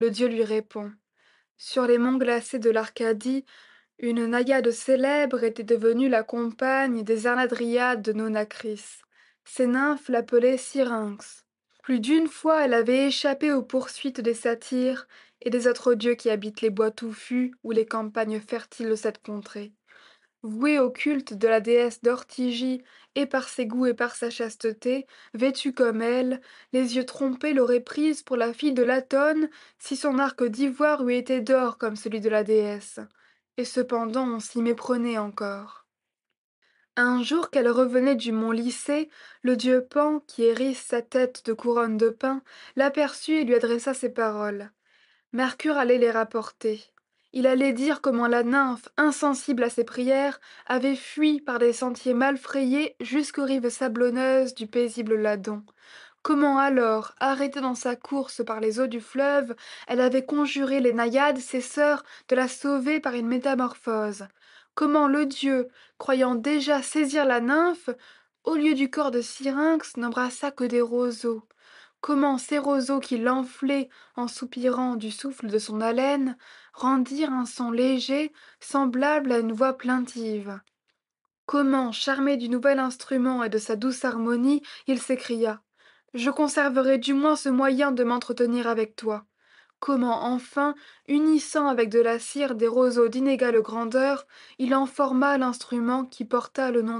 Lecture de la métamorphose de Syrinx · GPC Groupe 1